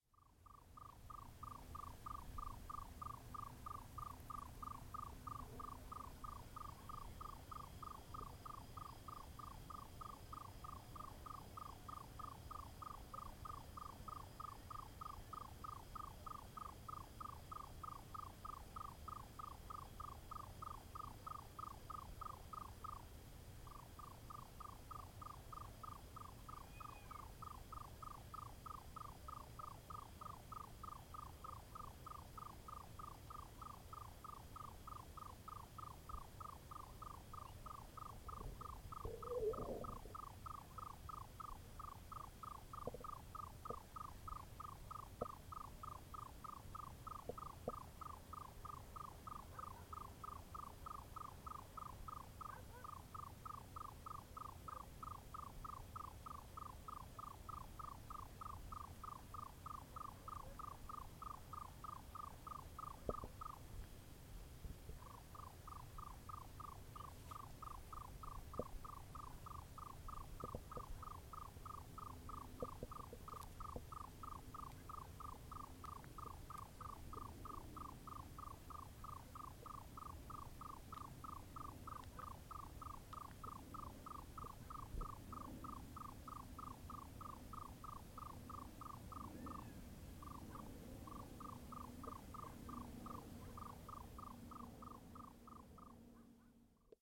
نام انگلیسی: Egyptian Nightjar
نام علمی: Caprimulgus aegyptius
آواز:
03.Egyptian Nightjar.mp3